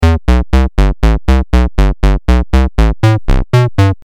缓慢的嘻哈循环，60 Bpm
描述：用音序器和Audacity制作的60Bpm嘻哈节拍循环。
标签： 60 bpm Hip Hop Loops Bass Loops 689.11 KB wav Key : Unknown
声道立体声